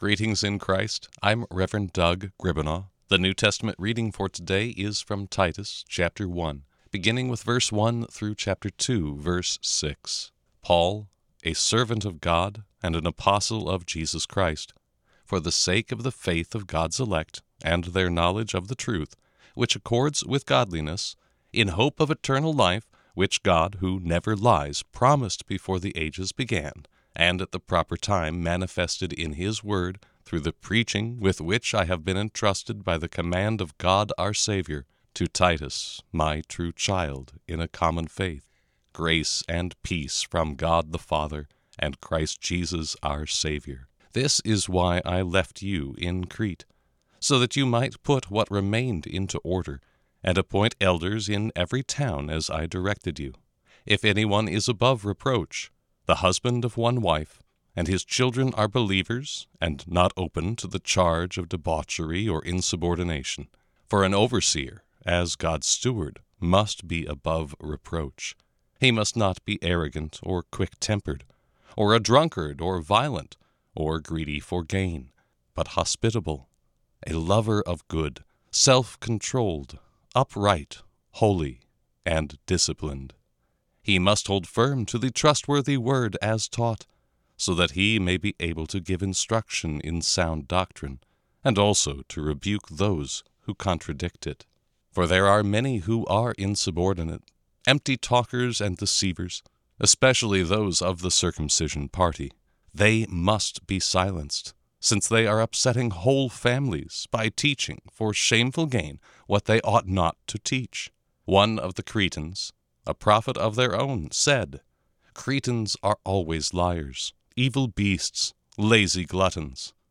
Morning Prayer Sermonette: Titus 1:1-2:6
Hear a guest pastor give a short sermonette based on the day’s Daily Lectionary New Testament text during Morning and Evening Prayer.